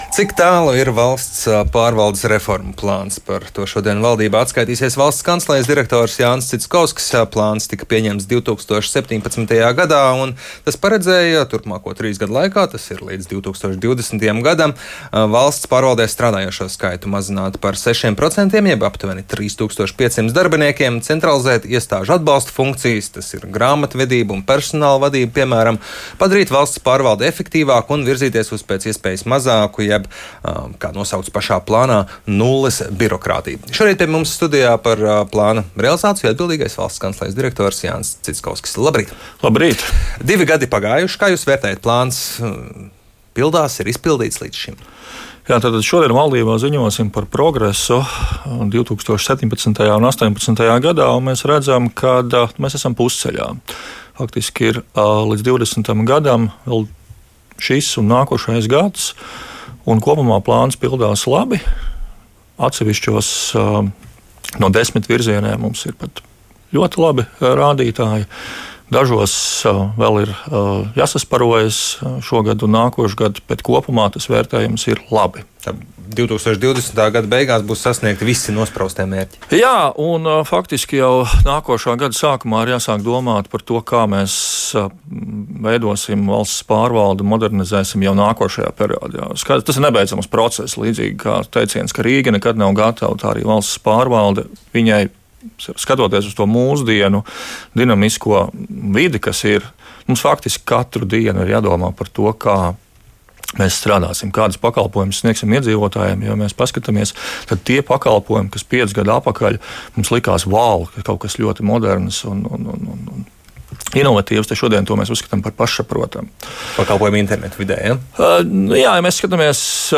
Reformu plāns valsts pārvaldē kopumā pildās labi un šobrīd ir aptuveni pusceļā, tā intervijā Latvijas Radio norādīja Valsts kancelejas direktors Jānis Citskovskis.